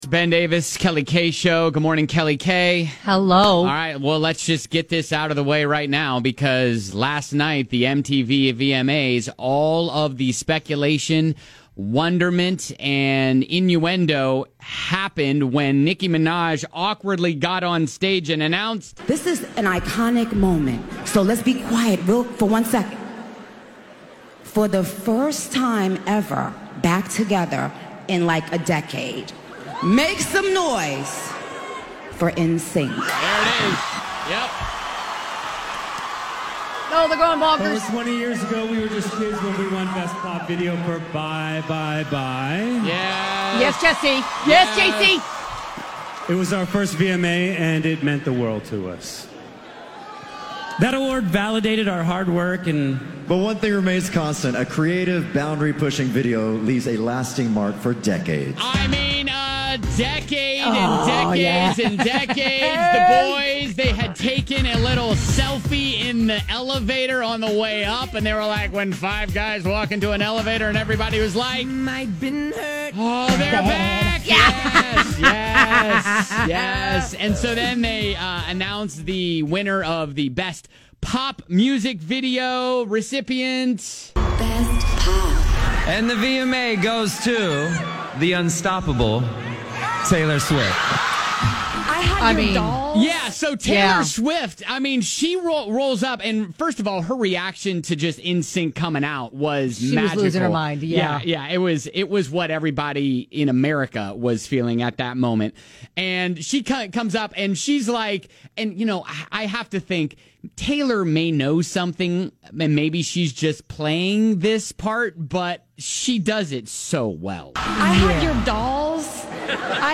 And in Group Therapy, is bribing or incentivizing your kids to get good grades a good idea? And we played more pop songs on piano to give out Fall Out Boy tickets.